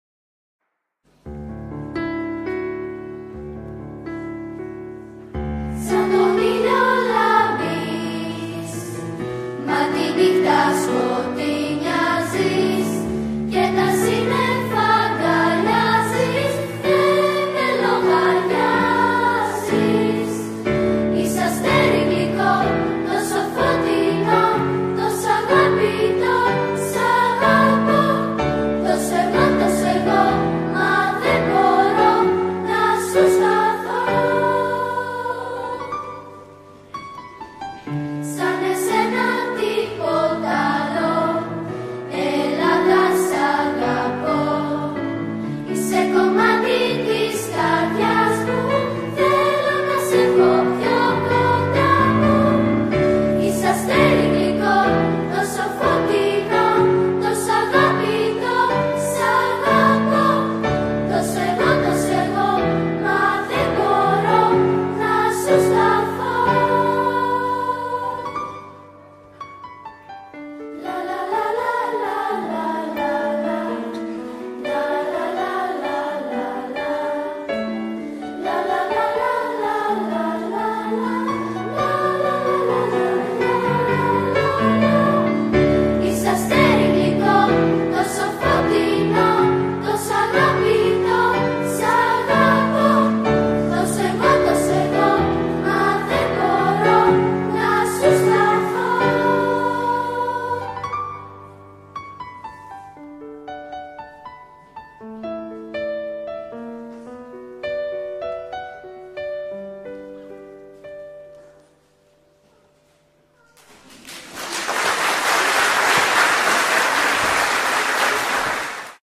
Σαν-τον-ήλιο-λάμπεις-Παιδική-χορωδία-Λαγυνών.mp3